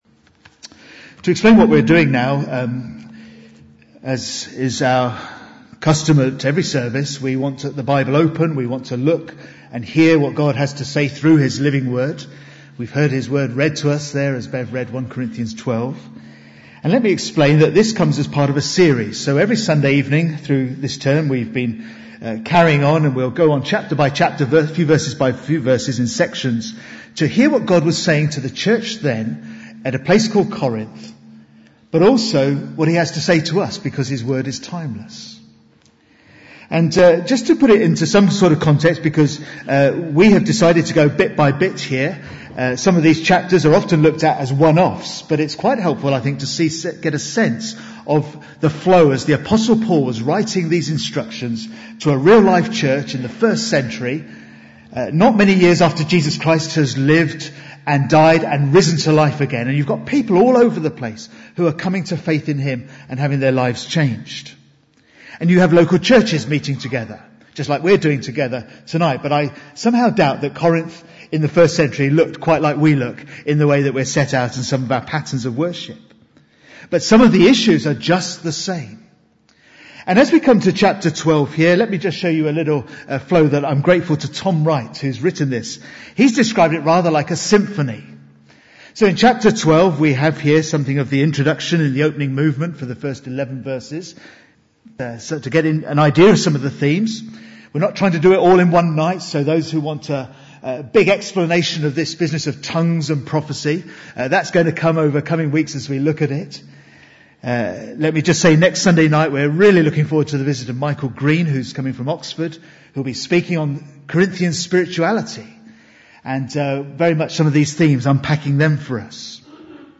One giver, many gifts - Cambray Baptist Church